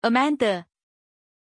Pronuncia di Amanda
pronunciation-amanda-zh.mp3